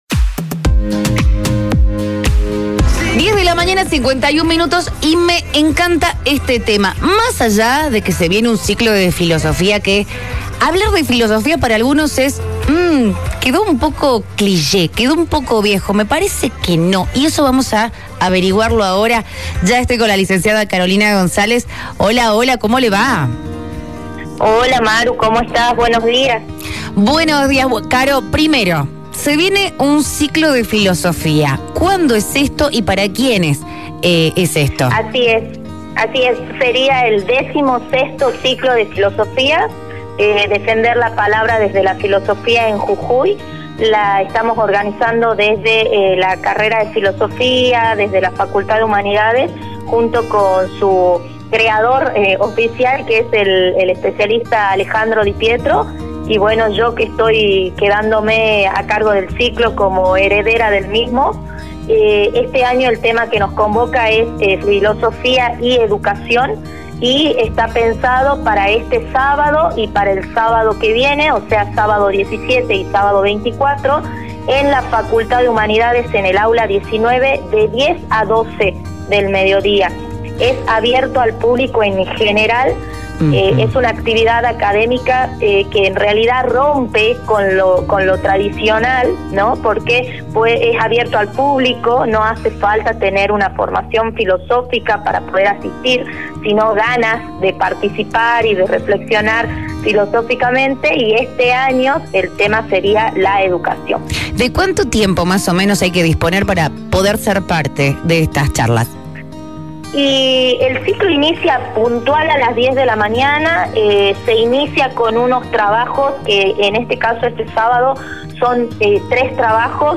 Con la licenciada comenzamos con el primer interrogante: “¿qué es la filosofía?” a lo cual explicó que “es muy difícil definir a la filosofía ya que la filosofía se debe vivir”